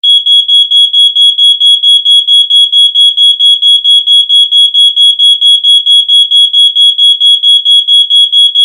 smoke-detector_25055.mp3